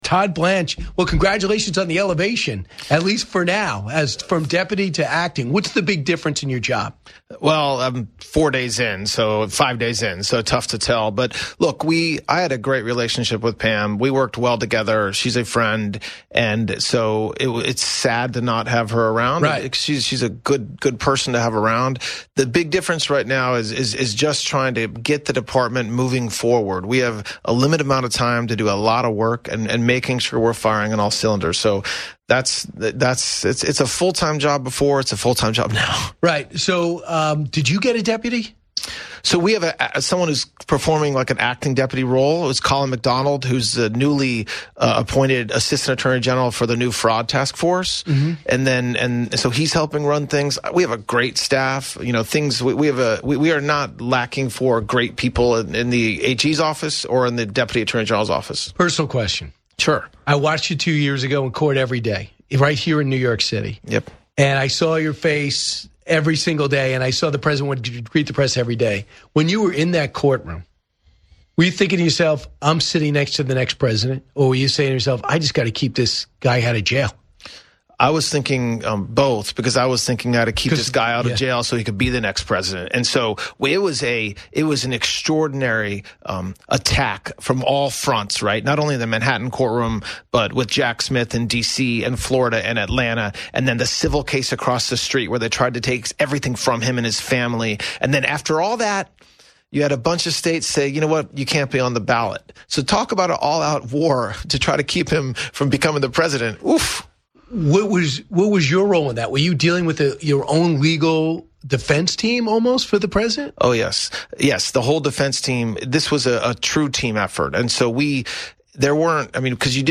Acting Attorney General Todd Blanche joins the show to discuss the ongoing efforts to overhaul the Department of Justice and expose the origins of the "Russia Hoax." Blanche provides an inside look at the transition of power and the fight for transparency within the federal government.